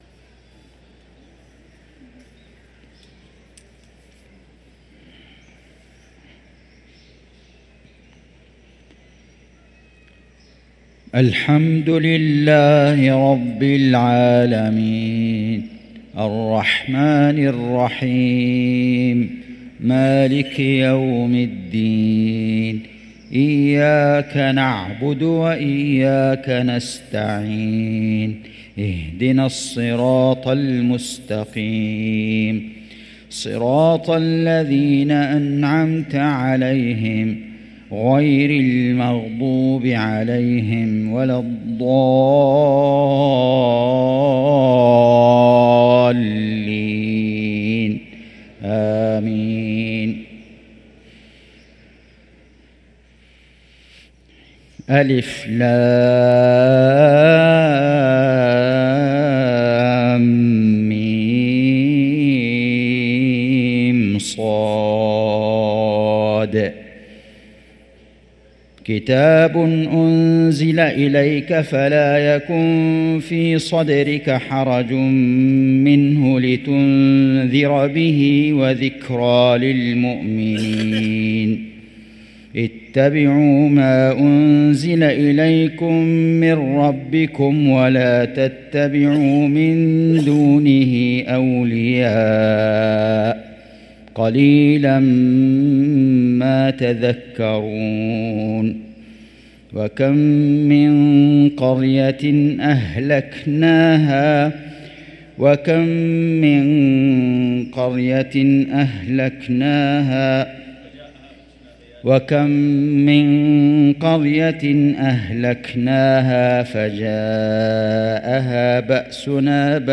صلاة العشاء للقارئ فيصل غزاوي 4 رجب 1445 هـ
تِلَاوَات الْحَرَمَيْن .